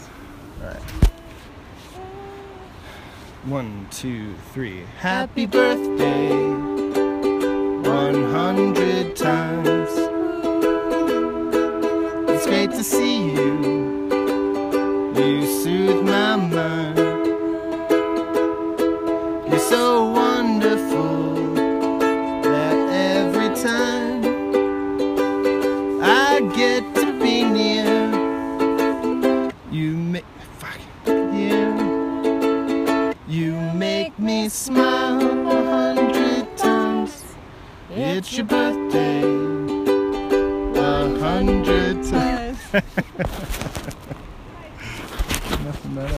Field Recordings
These were our first couple of practices.